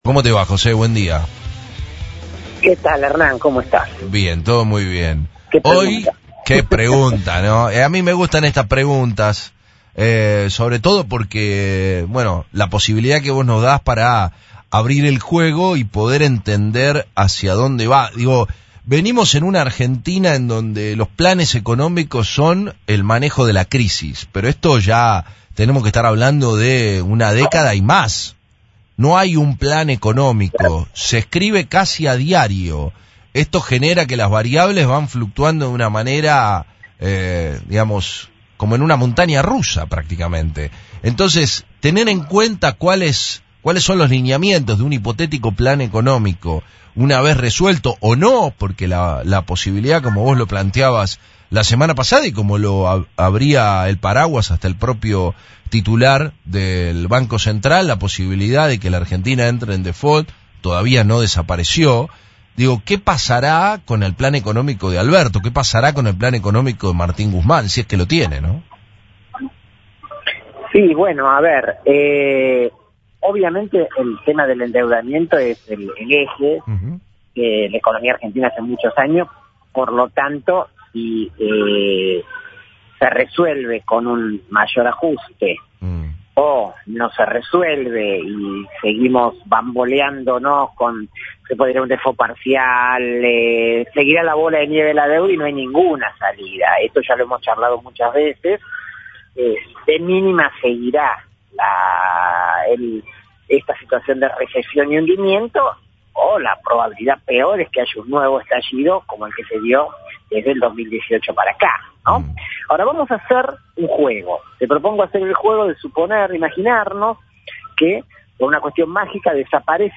Para eso, insistió, en diálogo con FRECUENCIA ZERO , con un sistema de nacionalización, donde se incluya a la Banca; y la eliminación de la Ley de Entidades Financieras.